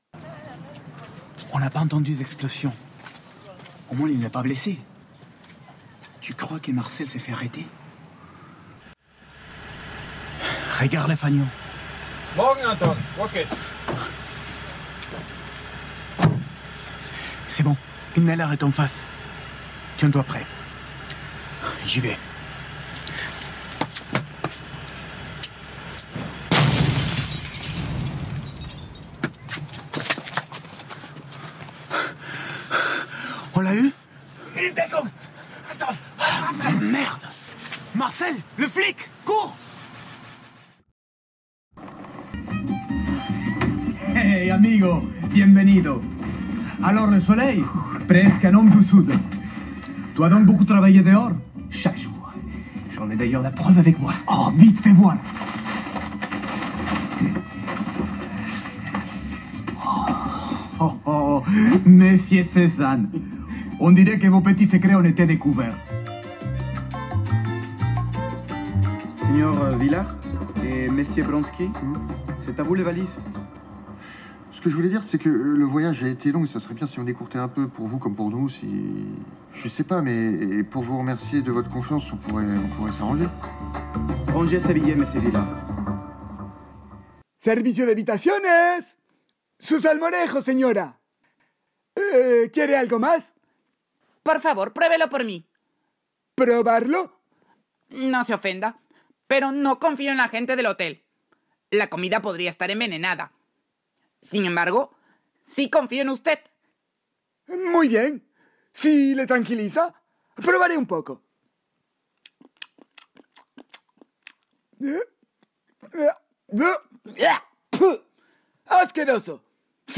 Voix off
- Baryton